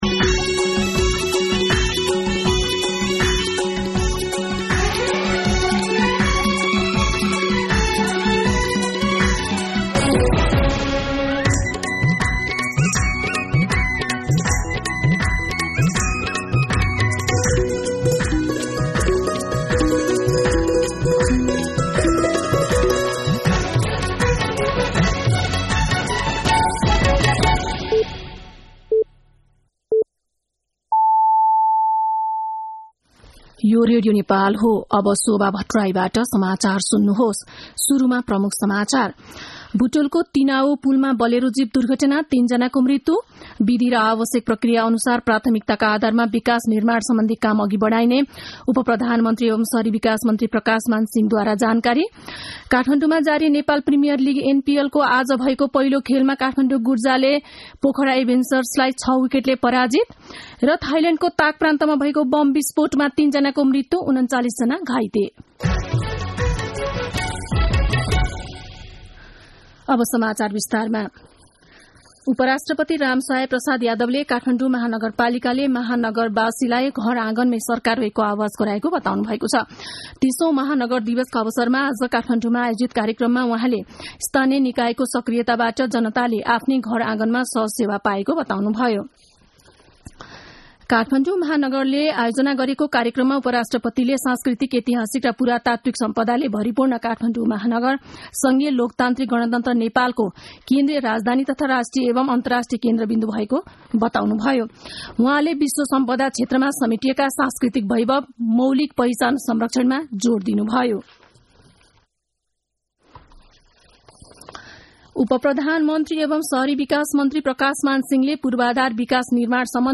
दिउँसो ३ बजेको नेपाली समाचार : ३० मंसिर , २०८१
3-pm-Nepali-News-1.mp3